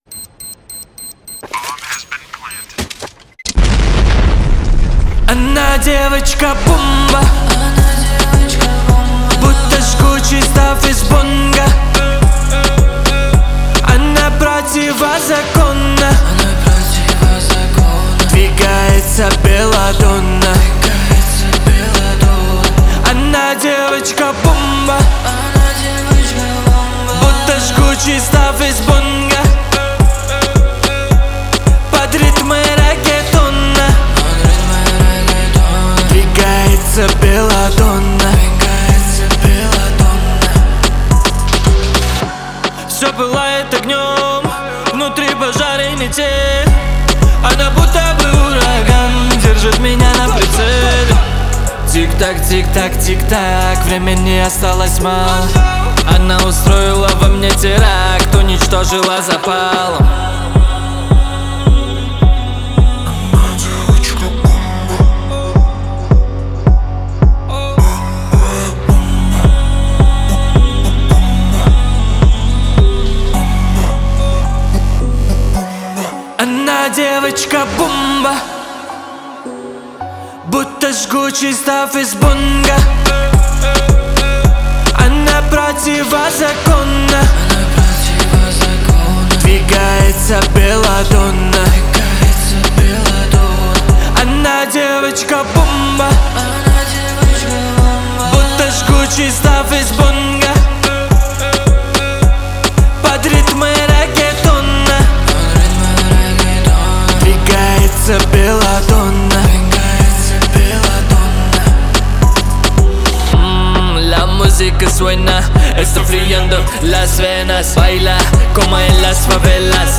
это зажигательная песня в жанре поп